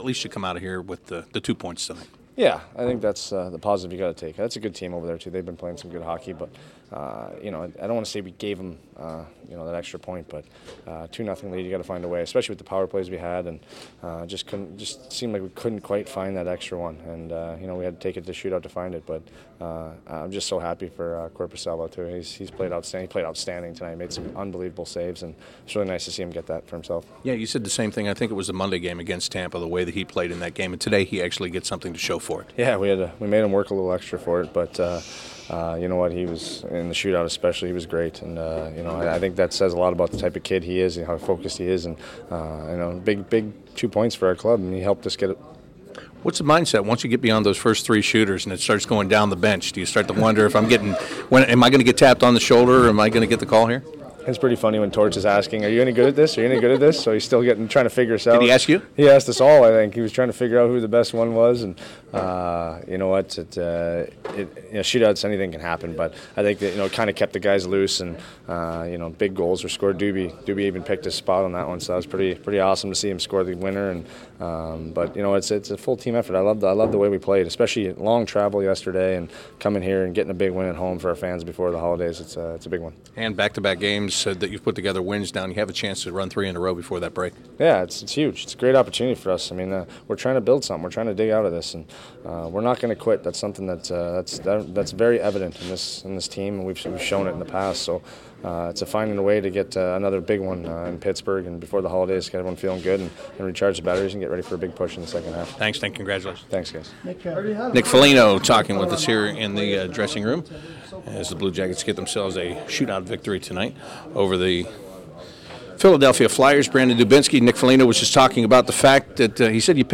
Nick Foligno, Brandon Dubinsky and Joonas Korpisalo talk about their six round shootout win over the Philadelphia Flyers